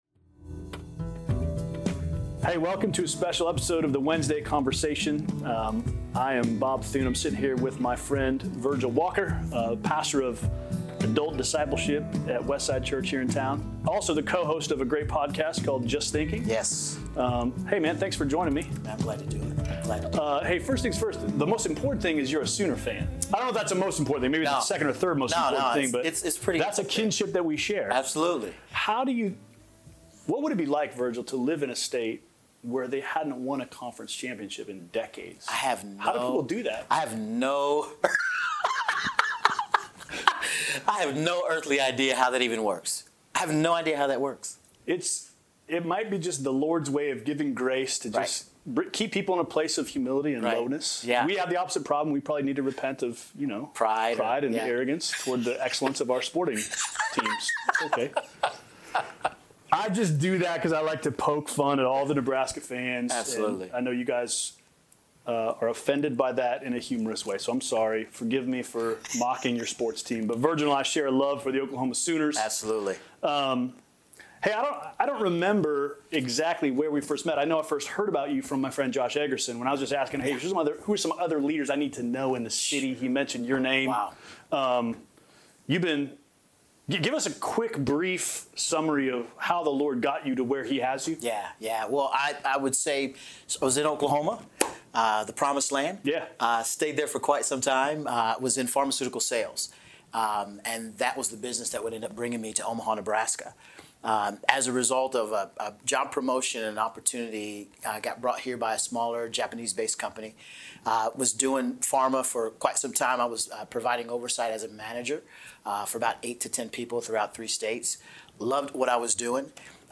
A Conversation About Race